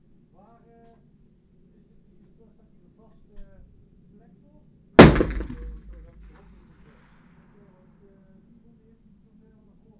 Environmental
Streetsounds
Noisepollution
UrbanSoundsNew / 01_gunshot /shot556_70_ch01_180718_162941_68_.wav